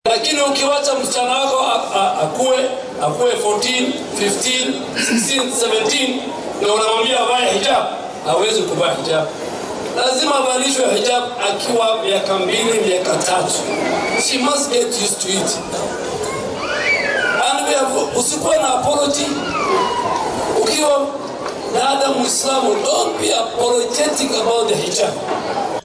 Hadalkaan ayuu jeediyay xili uu ku sugna gobolke xeebta ee dalka gaar ahaana ismaamulka Kilifi.